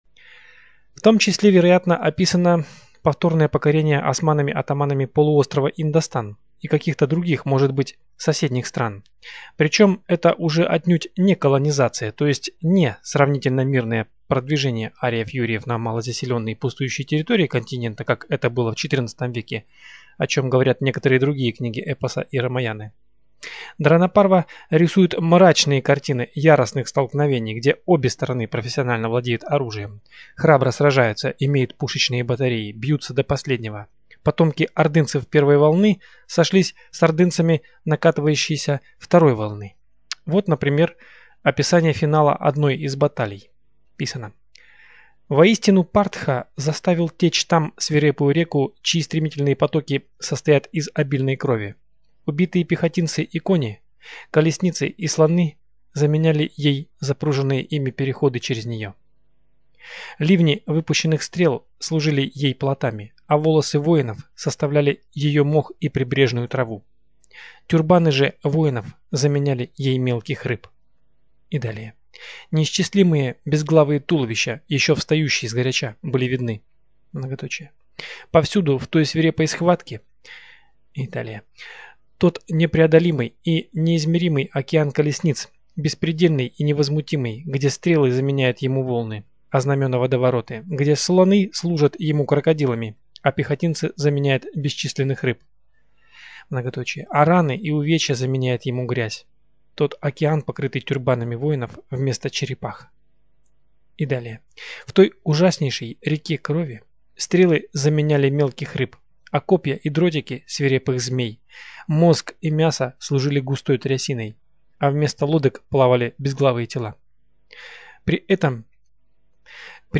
АУДИОКНИГИ